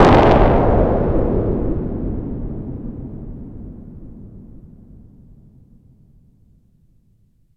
explo3.wav